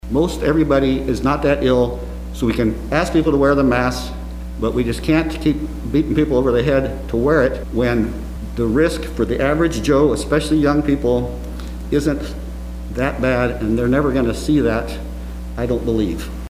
City Commissioner Linda Morse commented about it at a city commission meeting.
Here are his comments from that very same meeting.